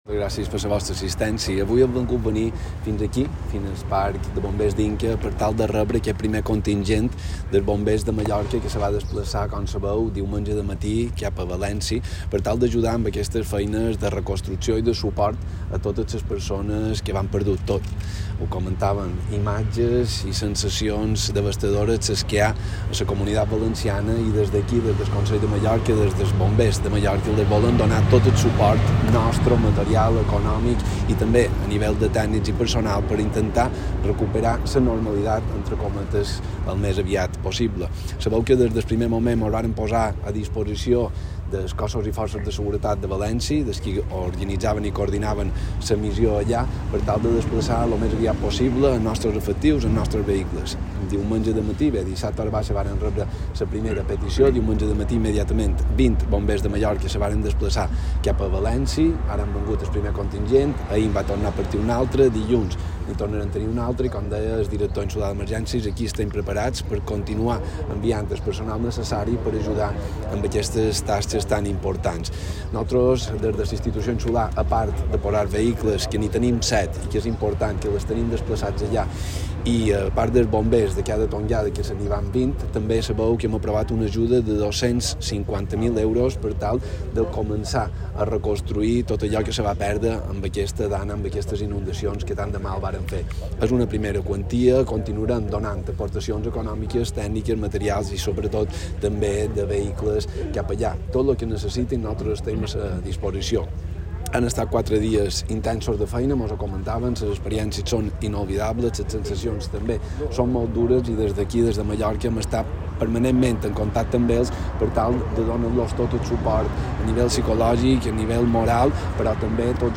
Declaracions del president de Consell de Mallorca, Llorenç Galmés.